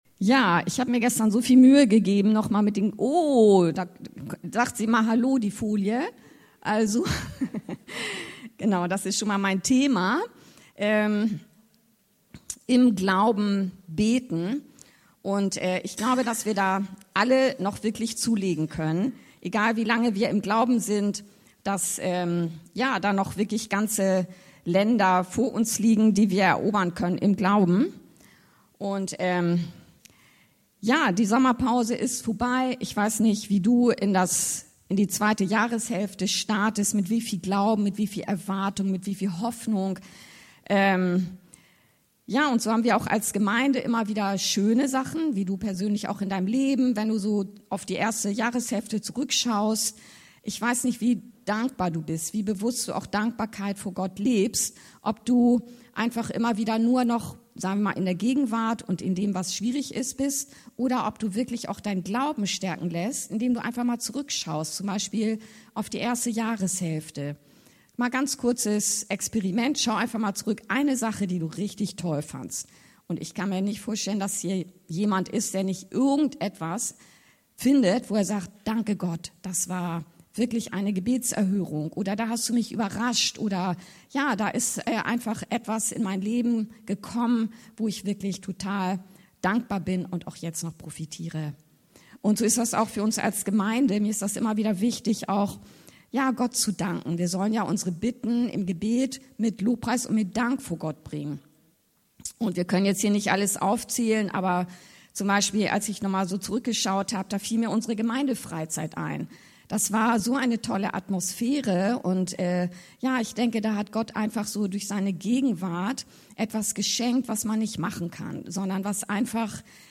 Predigten Podcast